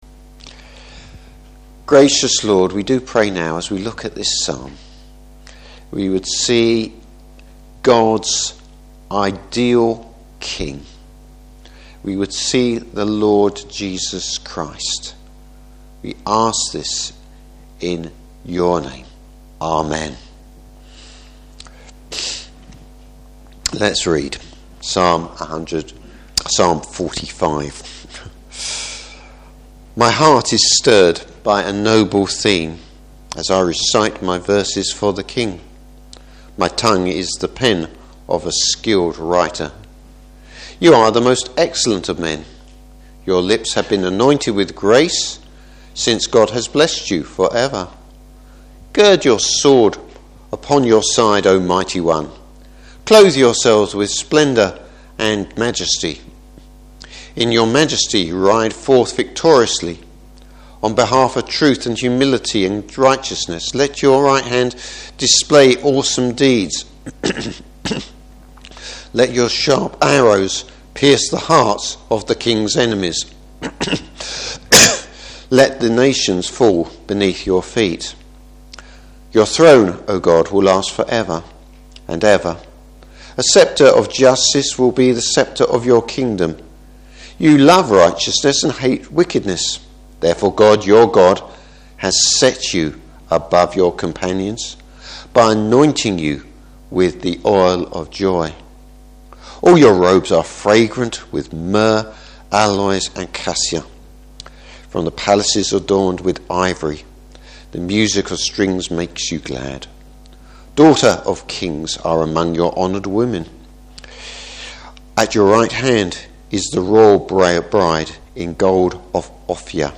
Service Type: Morning Service The righteous rule of Christ.